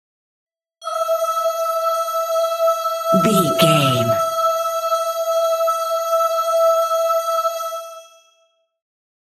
Angels Choir
Sound Effects
Atonal
joyful
dreamy
bright
calm